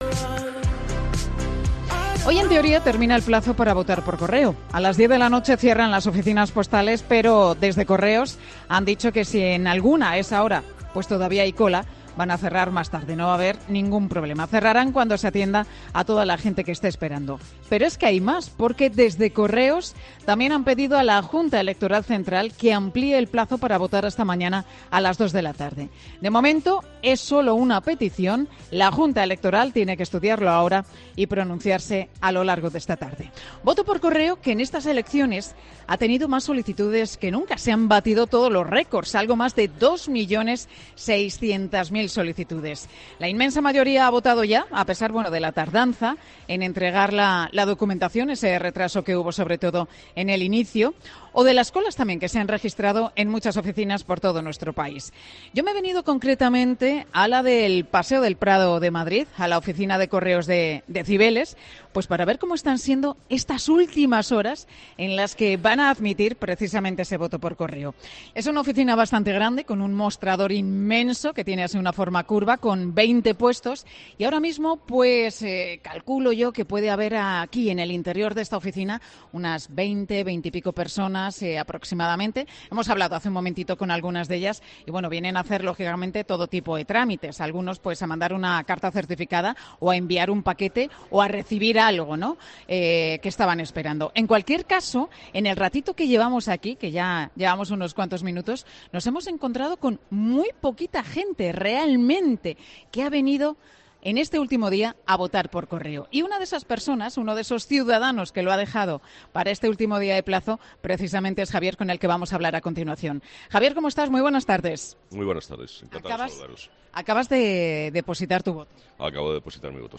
Pilar García Muñiz se ha trasladado hasta una de las 654 oficinas de nuestro país. Allí se encontraban unas 20 personas, haciendo todo tipo de trámites.